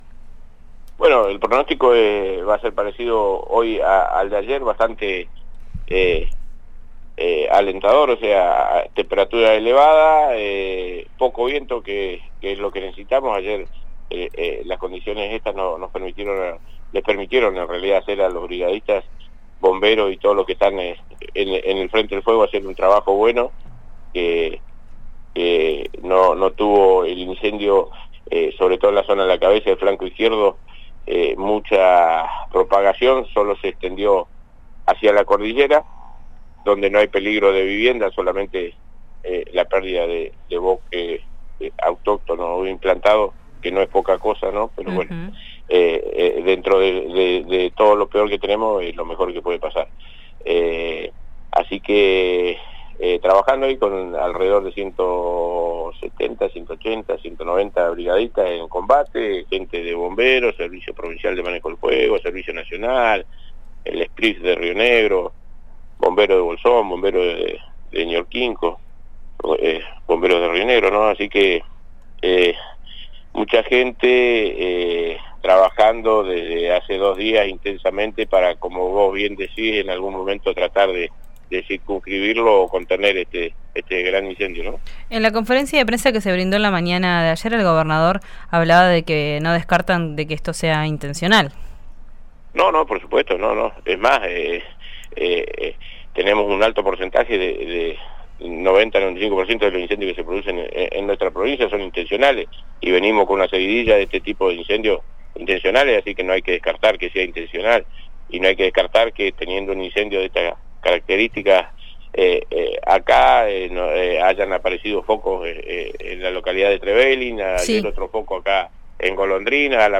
Escuchá al subsecretario de Protección Ciudadana de Chubut, Eduardo Pérez, en RÍO NEGRO RADIO.